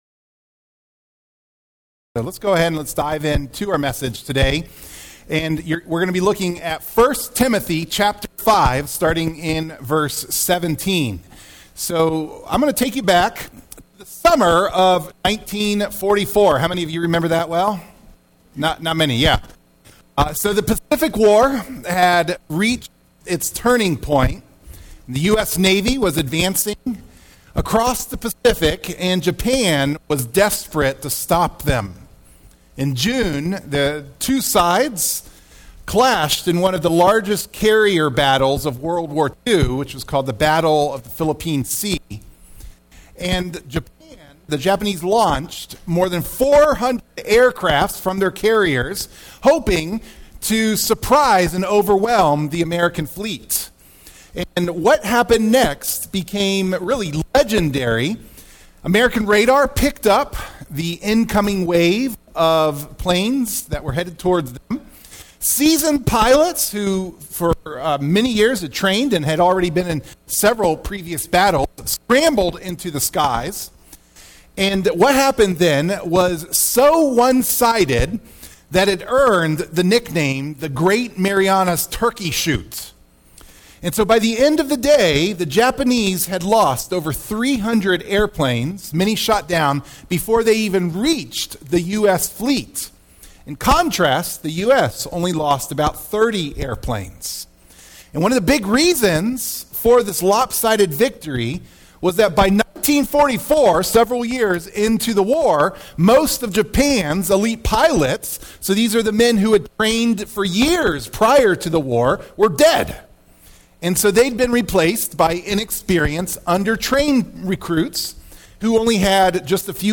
A healthy church guards the gospel through the way it honors, disciplines, and appoints its leaders. Sermon Clip The message notes for the sermon can be downloaded by clicking on the “save” button.